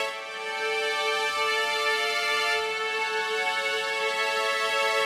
Index of /musicradar/80s-heat-samples/95bpm
AM_80sOrch_95-A.wav